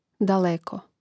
dalèko daleko